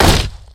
Kick9.wav